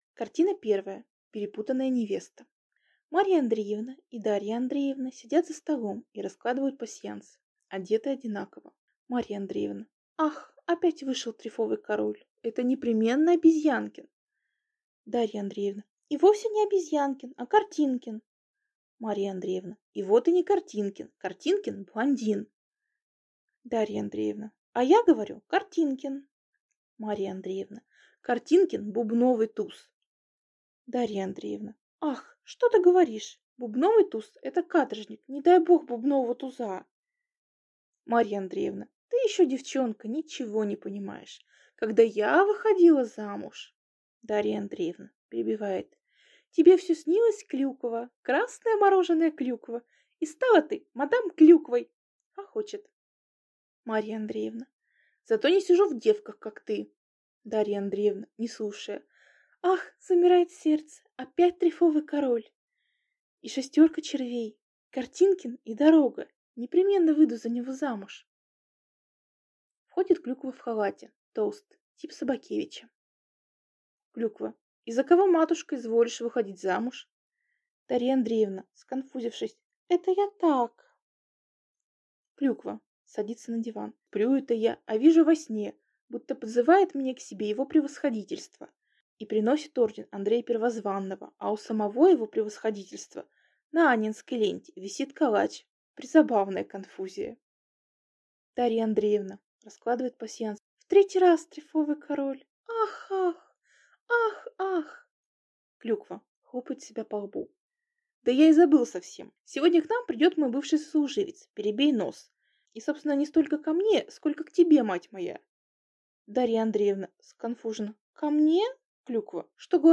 Аудиокнига Эволюция театра | Библиотека аудиокниг